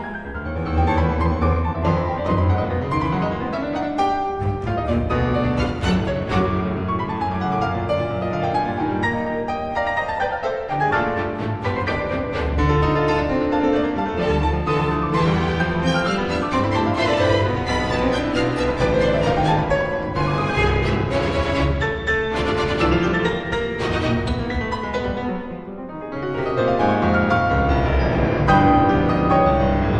"templateExpression" => "Musique orchestrale"